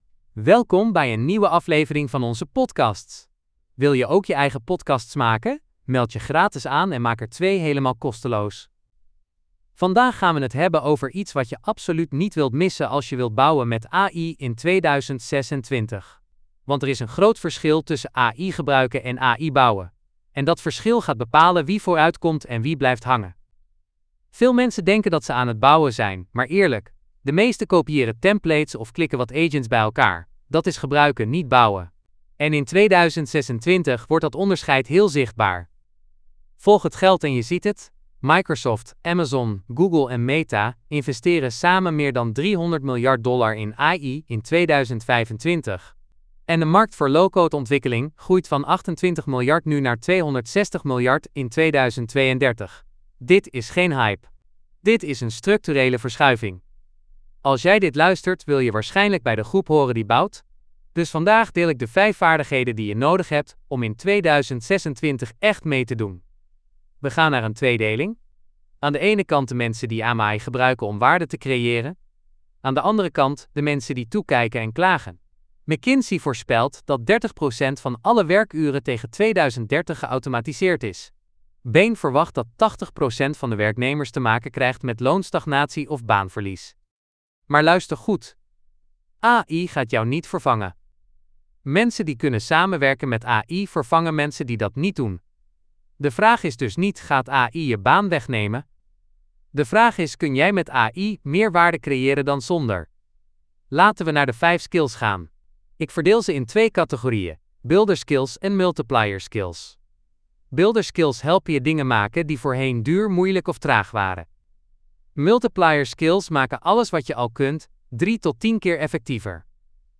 Podcast gegenereerd van geüploade script: 𝟮𝟬𝟮𝟲 𝘄𝗼𝗿𝗱𝘁 𝗵𝗲𝘁 𝘃𝗲𝗿𝘀𝗰𝗵𝗶𝗹 𝘁𝘂𝘀𝘀𝗲𝗻 𝗔𝗜 𝗴𝗲𝗯𝗿𝘂𝗶𝗸𝗲𝗻 𝗲𝗻 𝗔𝗜 𝗯𝗼𝘂𝘄𝗲𝗻